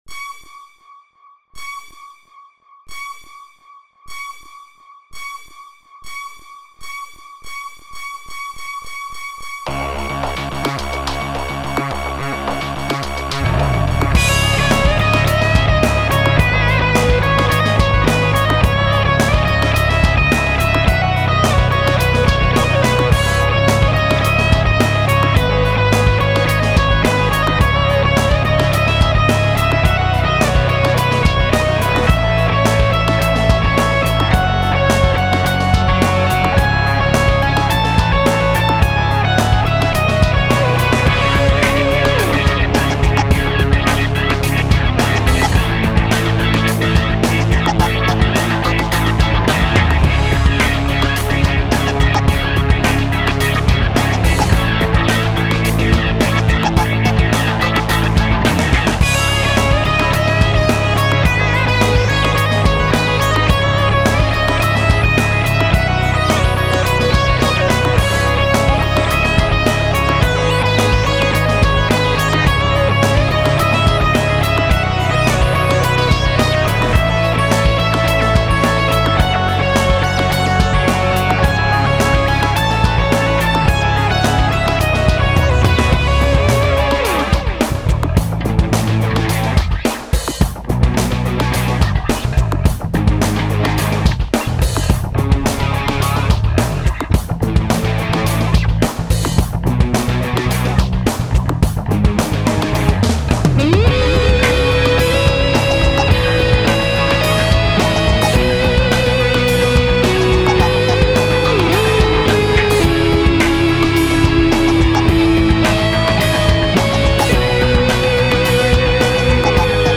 In the end, only the intro sound stayed.
Finally, I took the rock approach, it worked out fine.
That is the original speed when played on a NTSC machine.